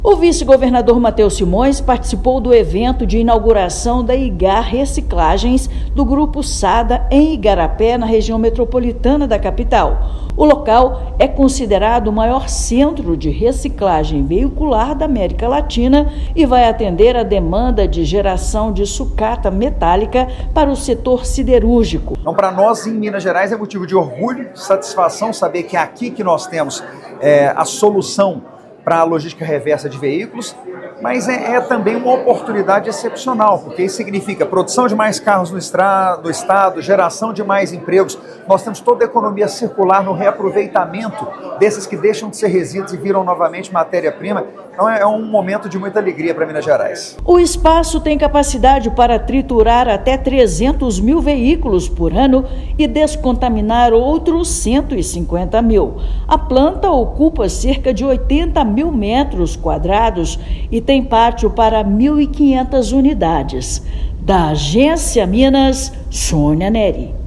[RÁDIO] Governo de Minas participa da inauguração do maior centro de reciclagem veicular da América Latina, em Igarapé
Espaço terá capacidade para triturar até 300 mil veículos por ano e descontaminar outros 150 mil. Ouça matéria de rádio.